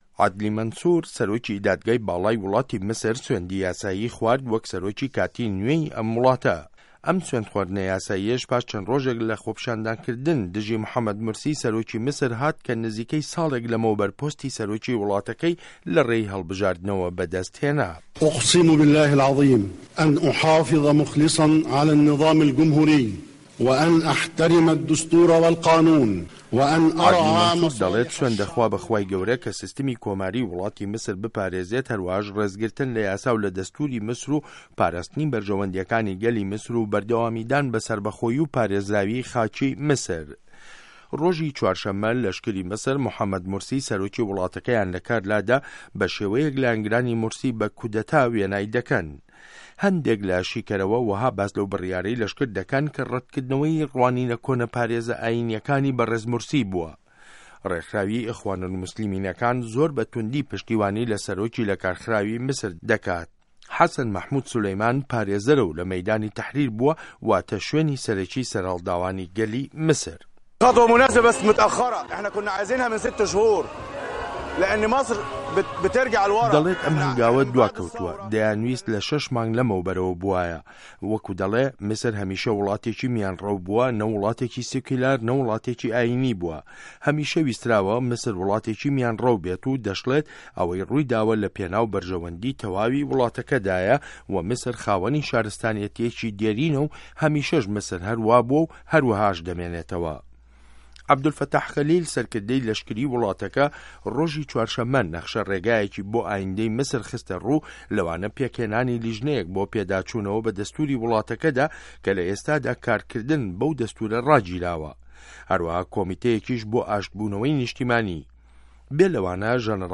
ڕاپـۆرتی میسر 1